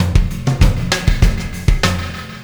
Pulsar Beat 04.wav